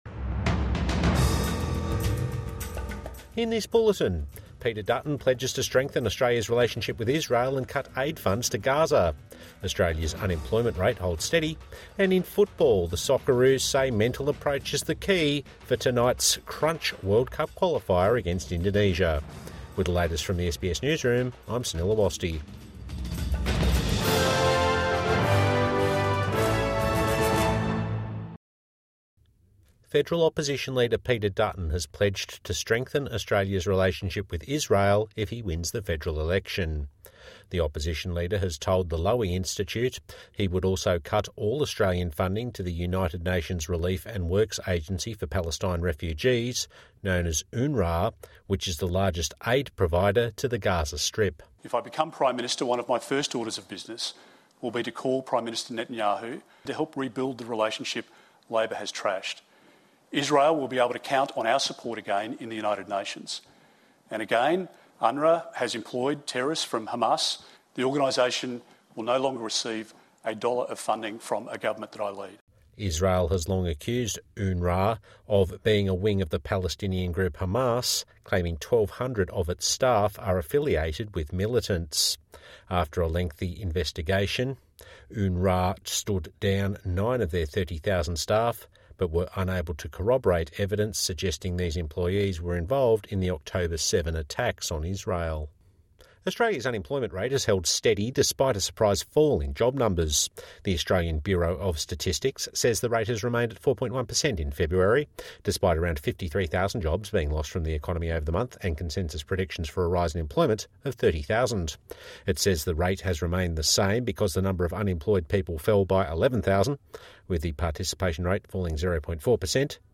Evening News Bulletin 20 March 2025